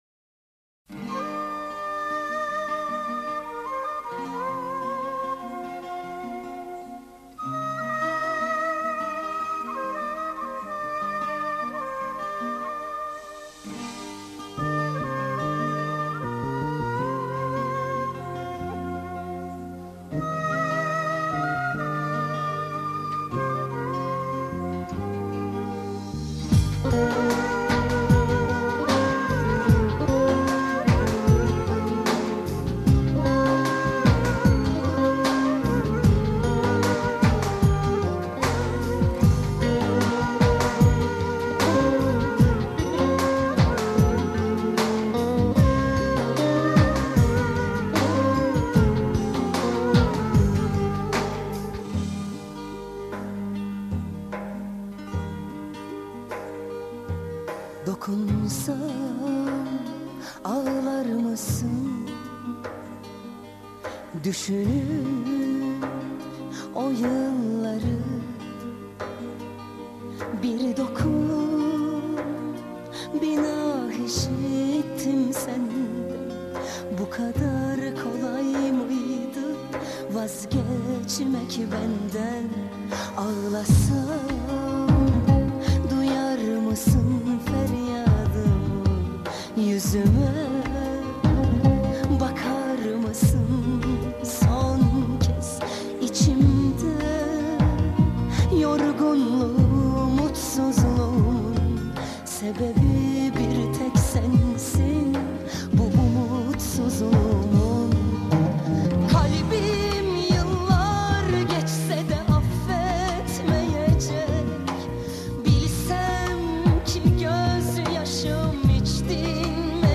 турецкий певец